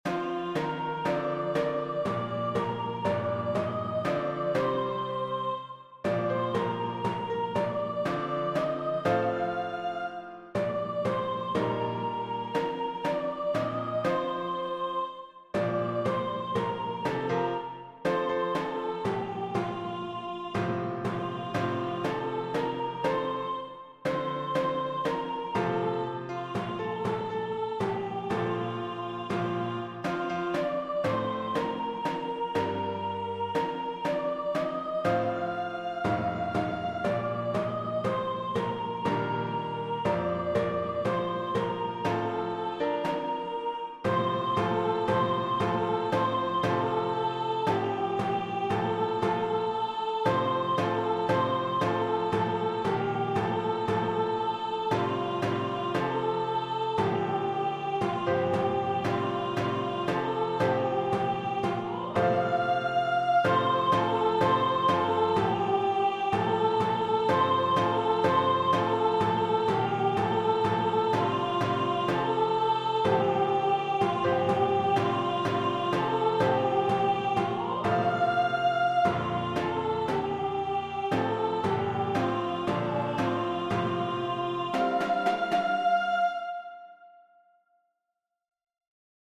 Malaysia_ISPRM-We-Will-piano-and-timpani.mp3